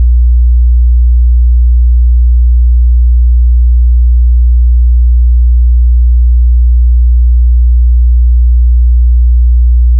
60hz.wav